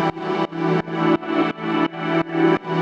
Index of /musicradar/sidechained-samples/170bpm
GnS_Pad-dbx1:4_170-E.wav